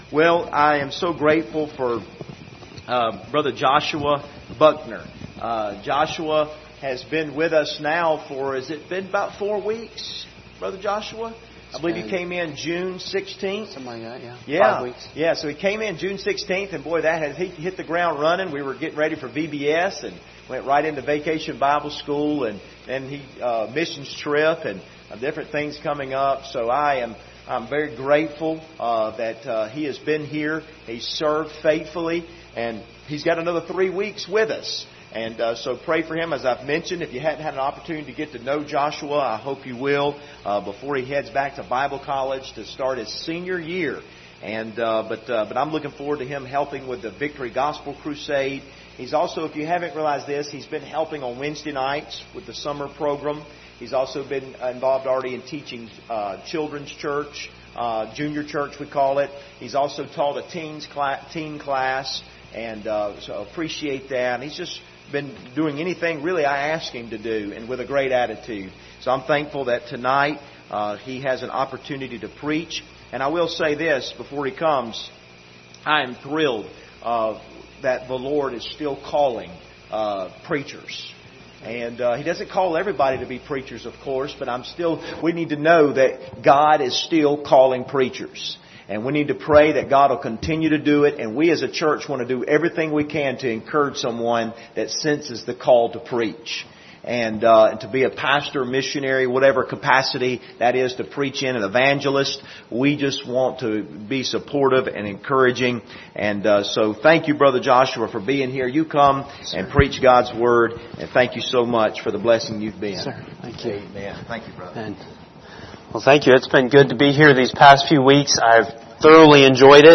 Passage: 2 Kings 2:1-15 Service Type: Sunday Evening Topics